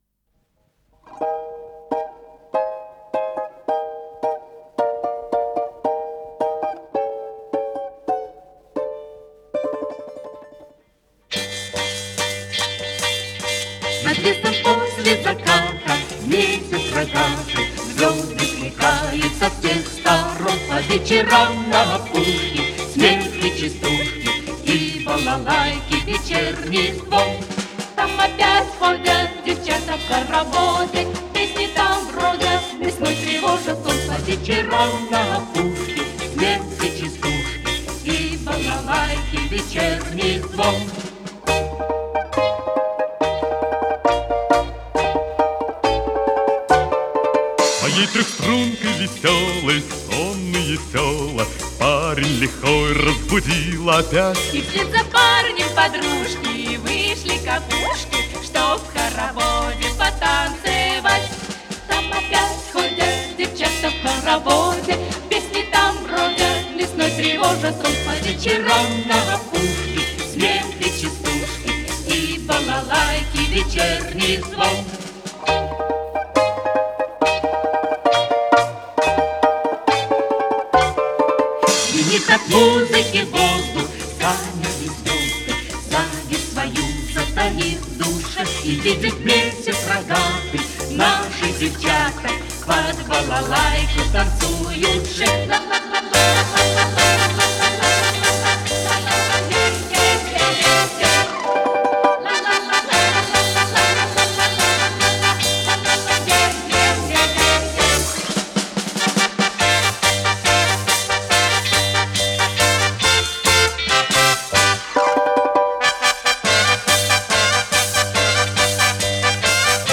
с профессиональной магнитной ленты
АккомпаниментИнструментальный ансамбль
Скорость ленты38 см/с